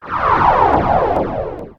Machine23.wav